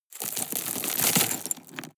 Royalty free sounds: Stones